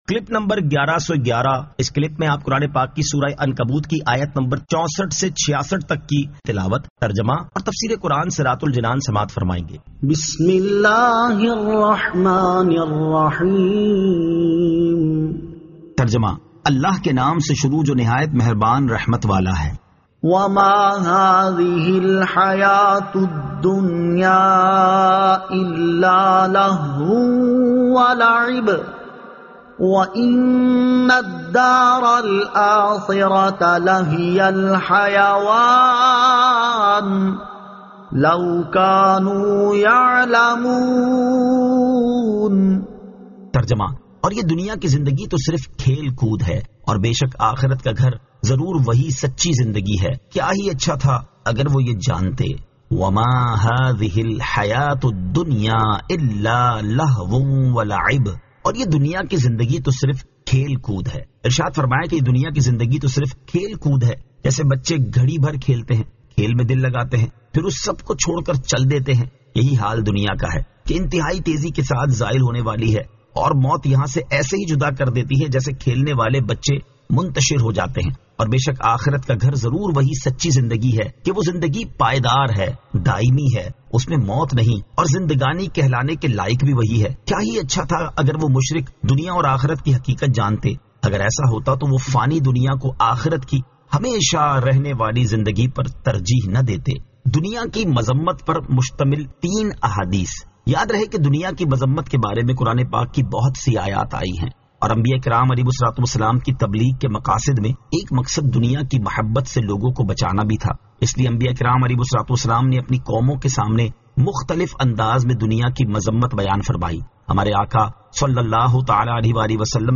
Surah Al-Ankabut 64 To 66 Tilawat , Tarjama , Tafseer